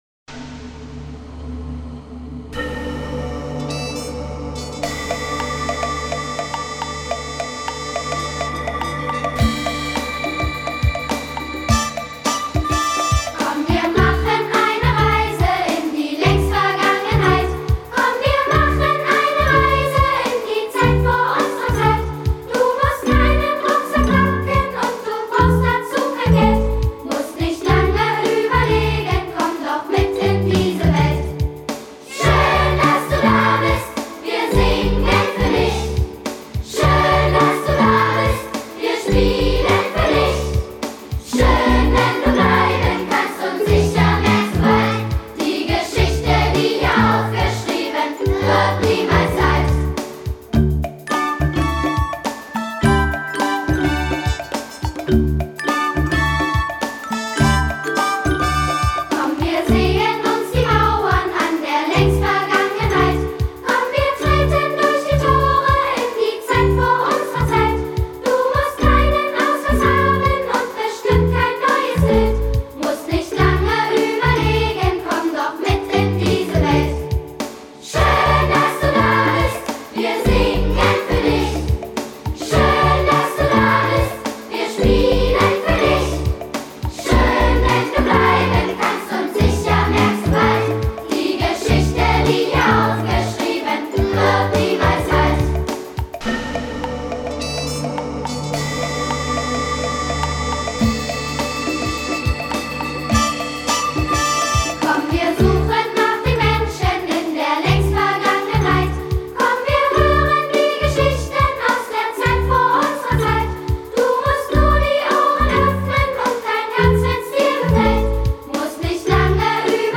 Gemeindelied
mit Akkordsymbolen und Klavierbegleitung